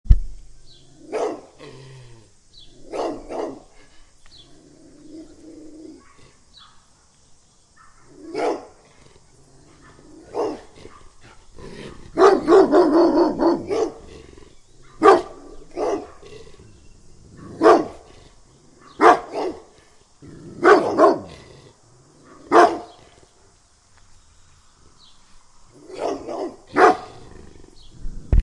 Neighbours Dogs Bouton sonore